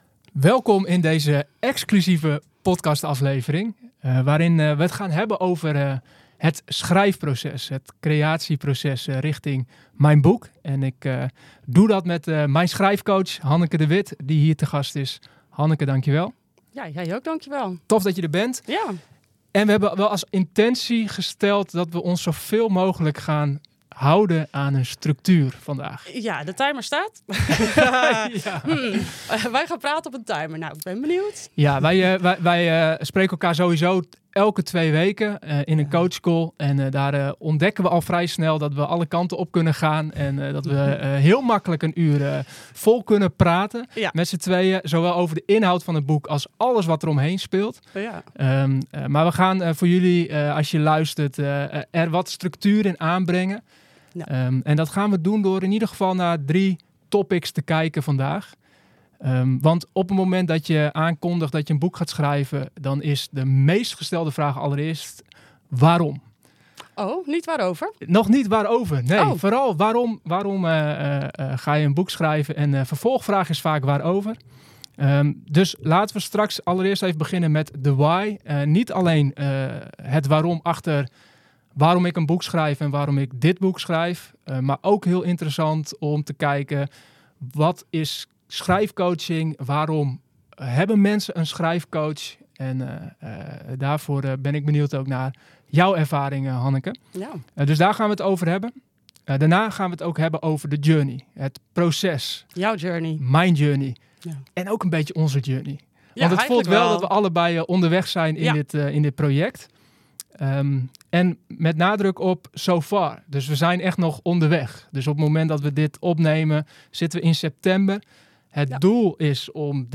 Een eerlijk gesprek over het schrijven van mijn boek